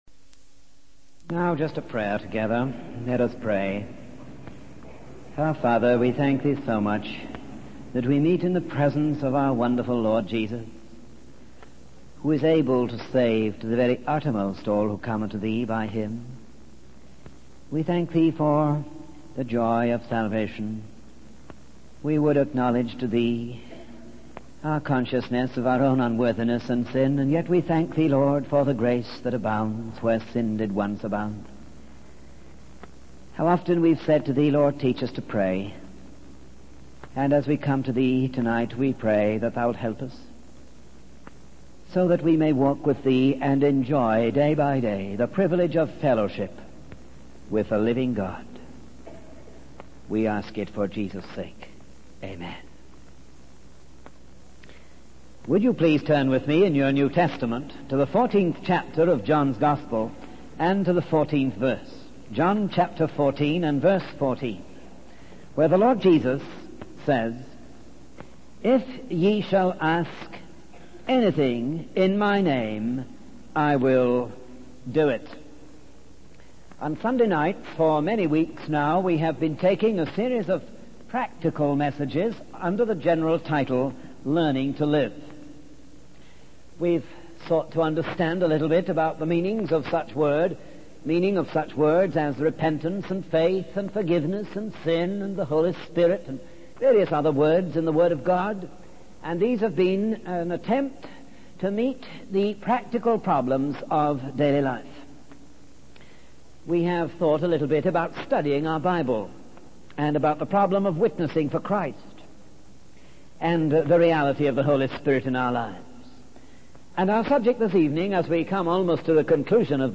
In this sermon, the speaker emphasizes the importance of prayer and highlights four basic principles of prayer.